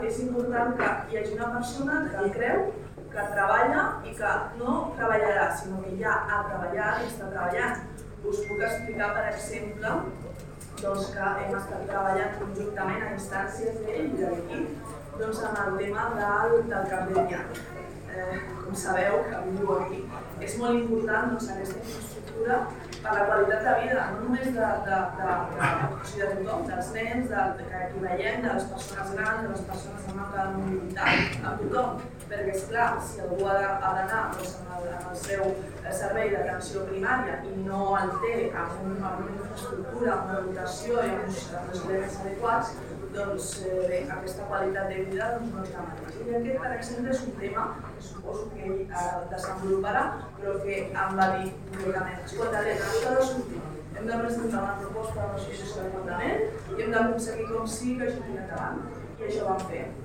PSC presenta a la Sala d’Exposicions del Casal la candidatura a les eleccions municipals
La diputada Helena Bayo va assegurar que el PSC de Tiana comptava amb un cap de llista i un equip compromès i va explicar la feina conjunta per portar fins al Parlament el tema del CAP de Tiana: